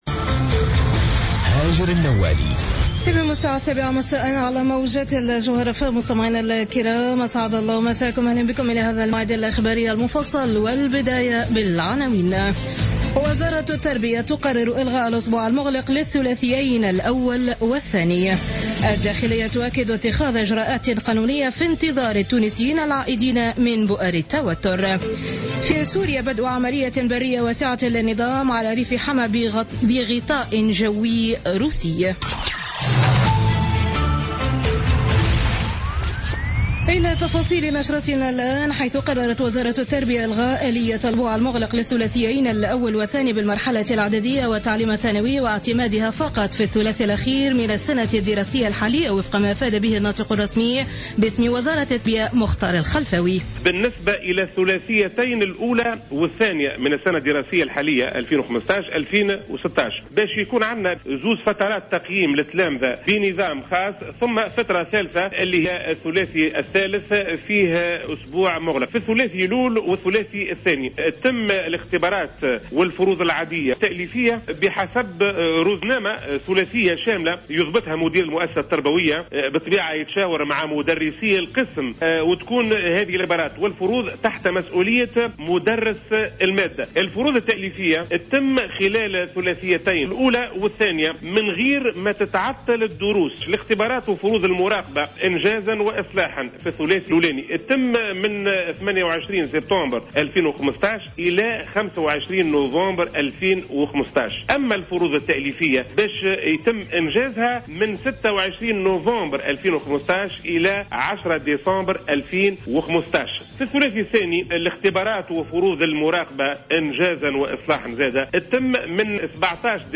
نشرة الأخبار السابعة مساء ليوم الإربعاء 7 أكتوبر 2015